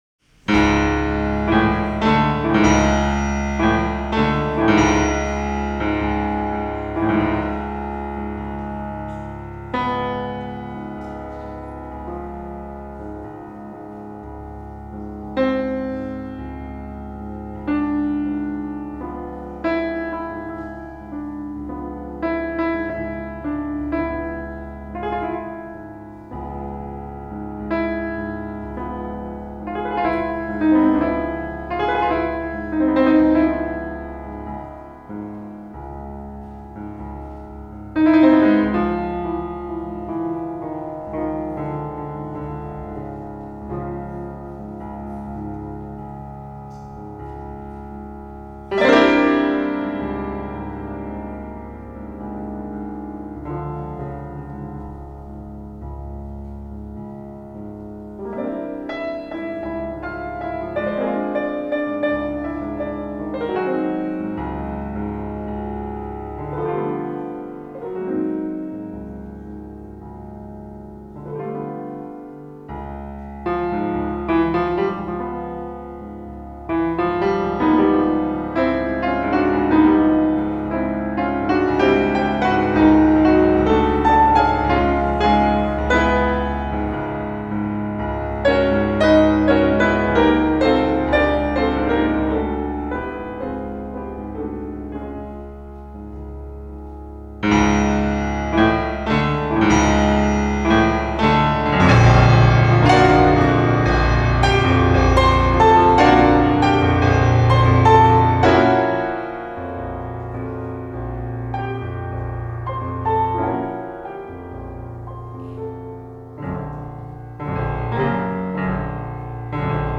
Roland Poentinen, izpildītājs
Kamermūzika
Liepājas koncertzāle Graudu ielā